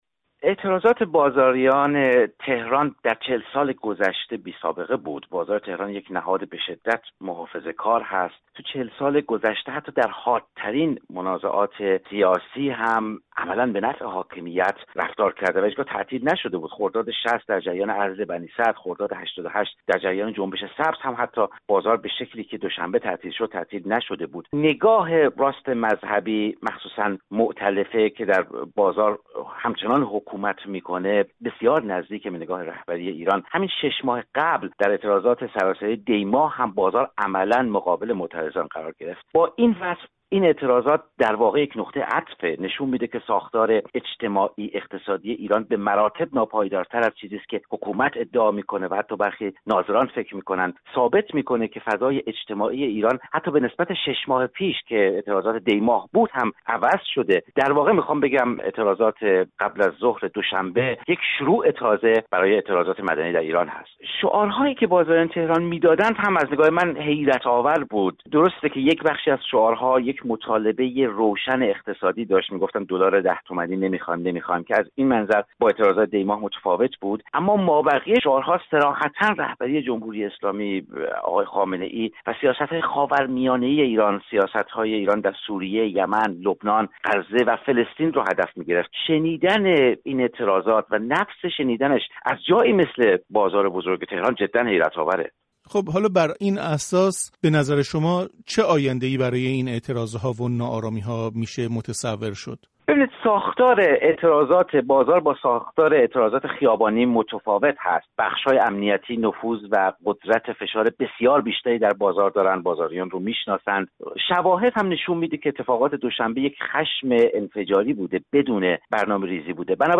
کارشناس سیاسی